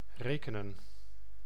Ääntäminen
IPA: /ˈreː.kə.nə(n)/